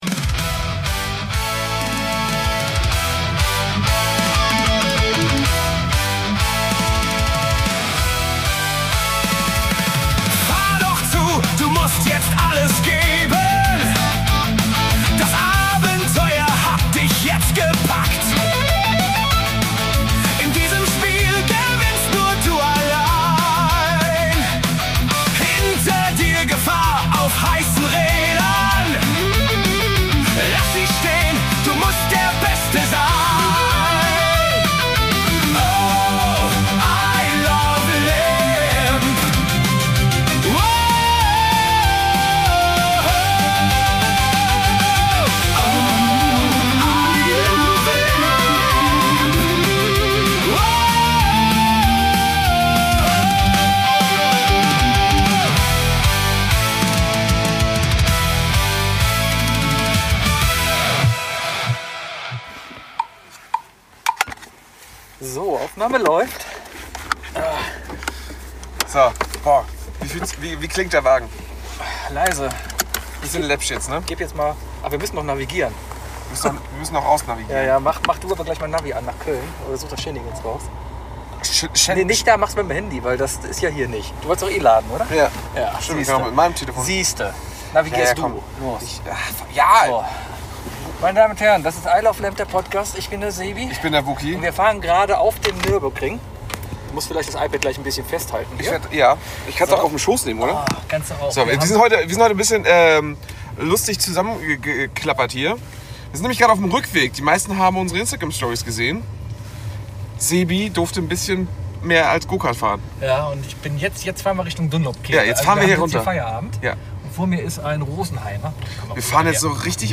So geflasht von den Erfahrungen hat man vielleicht vergessen zu gucken, dass die richtigen Mikros aufnehmen.